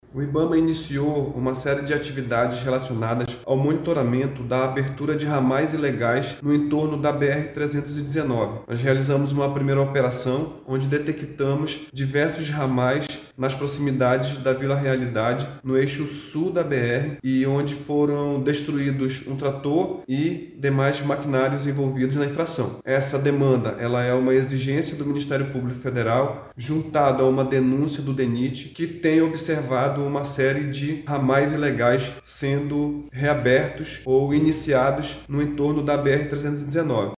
Também foram apreendidos e inutilizados geradores de energia que davam suporte logístico às ações criminosas, permitindo a permanência de pessoas nas áreas desmatadas, como explica o Superintendente do Ibama no Amazonas, Joel Araújo.